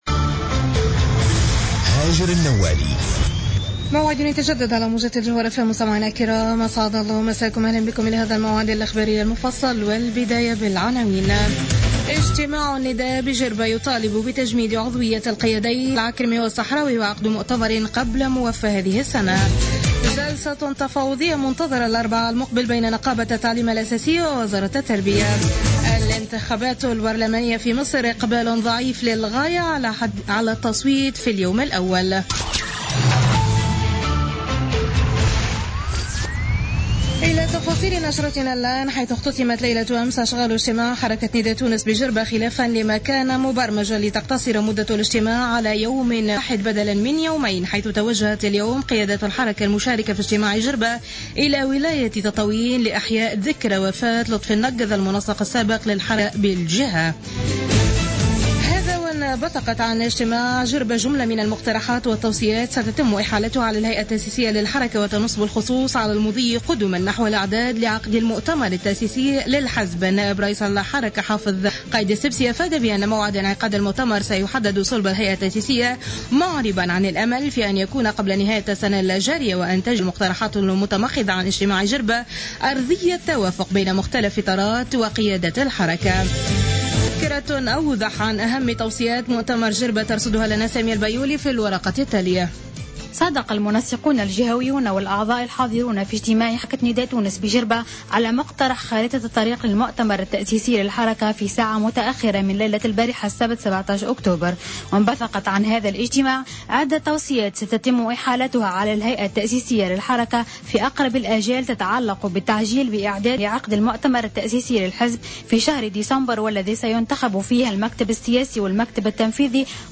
نشرة أخبار السابعة مساء الأحد 18 أكتوبر 2015